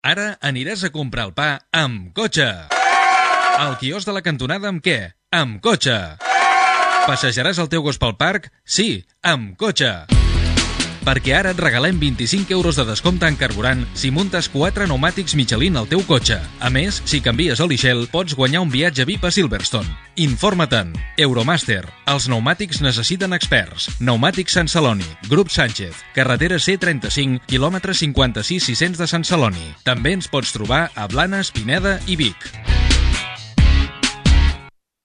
Anunci publicitari Gènere radiofònic Publicitat